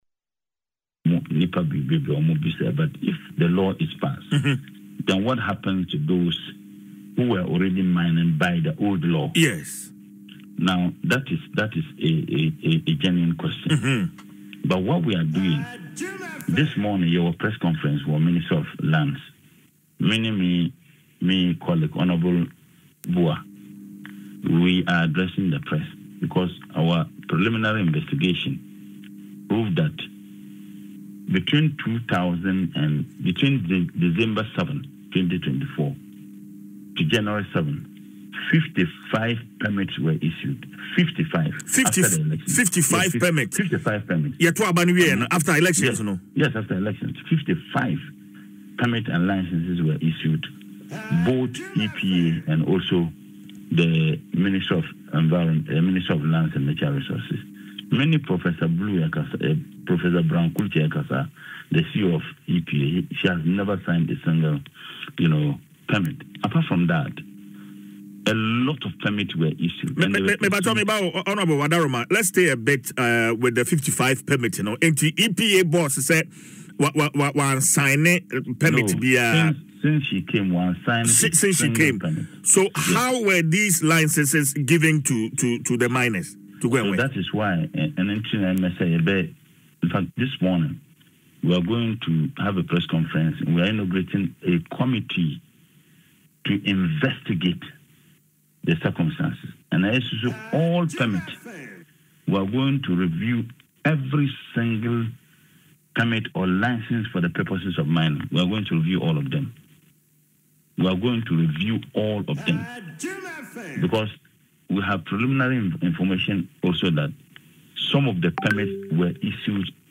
Dr. Muhammed revealed this on Adom FM’s Dwaso Nsem, citing preliminary investigations.